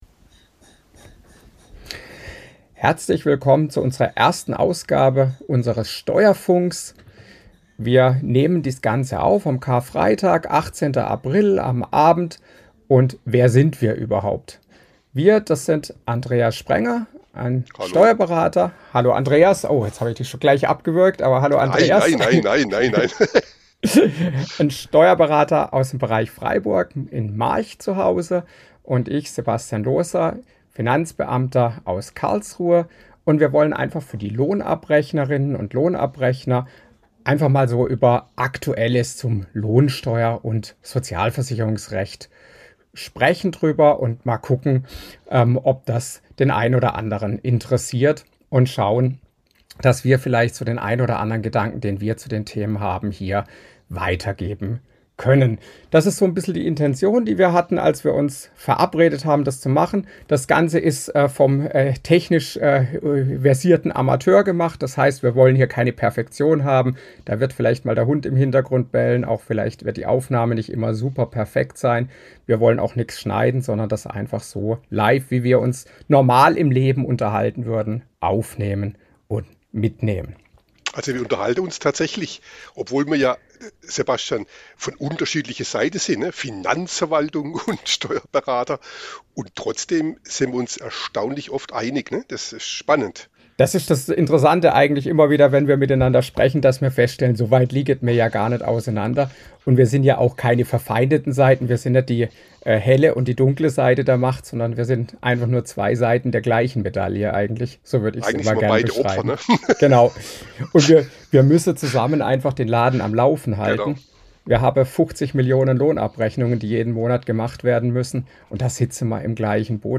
Die Autoren unterhalten sich über das PUEG sowie den Koalitionsvertrag von SPD und CDU.